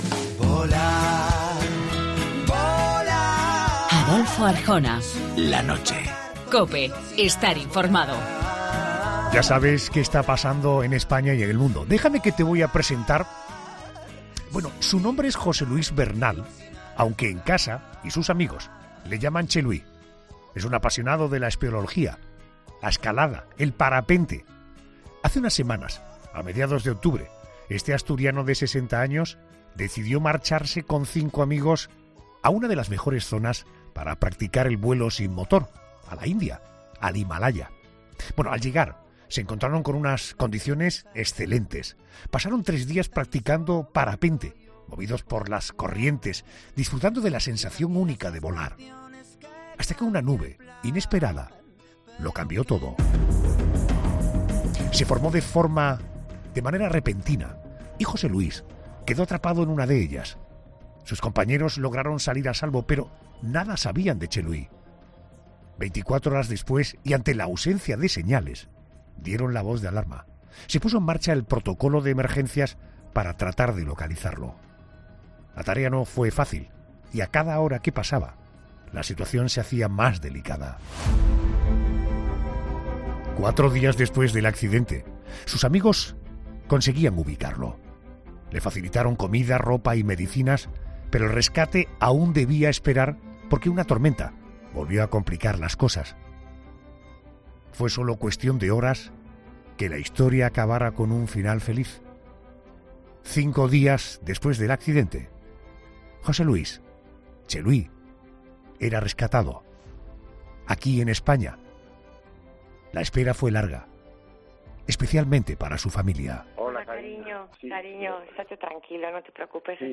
relata en 'La Noche de COPE' su experiencia con final feliz